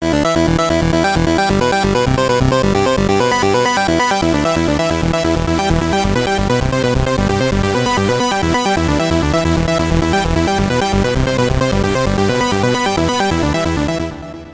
In this sample each oscillator got an own LFO for pulsewidth modulation. The modulation values for the right channel are inverted - this results into a nice stereo effect: